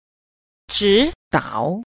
9. 指導 – zhǐdǎo – chỉ đạo